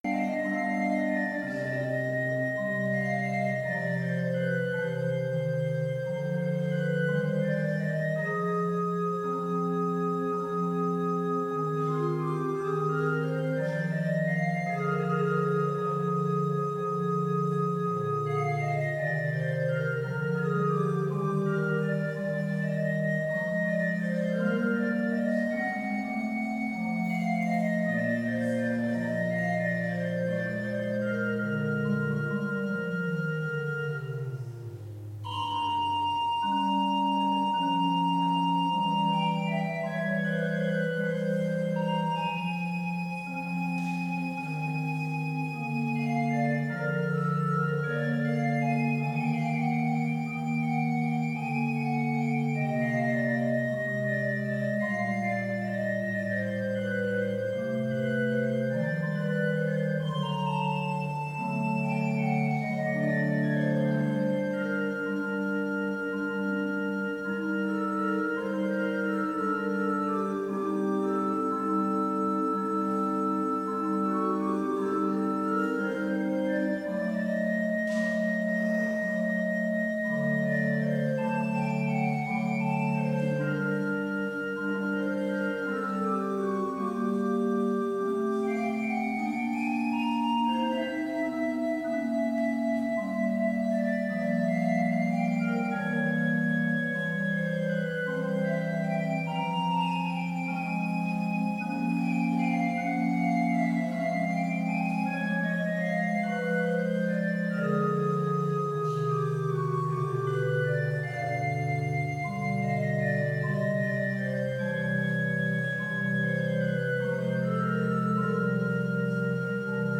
Complete service audio for Chapel - July 20, 2022